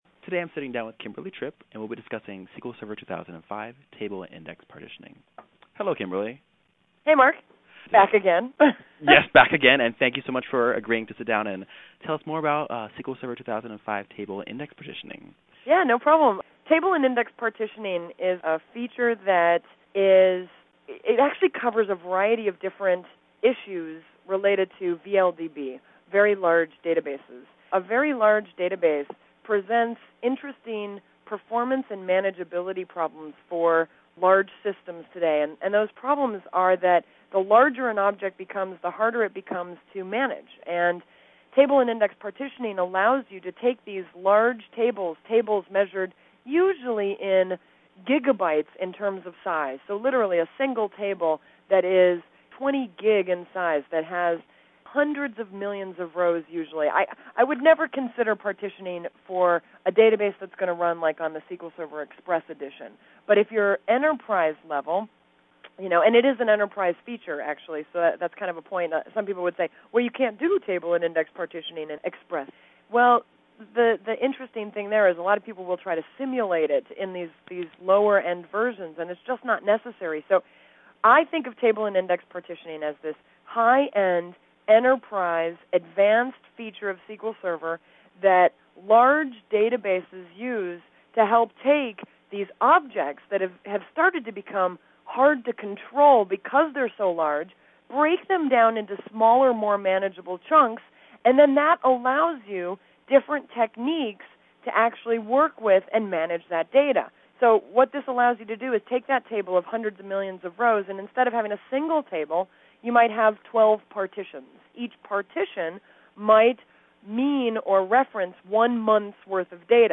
I’ve also posted the interviews here (DAT007 Interview (4MB mp3 file) and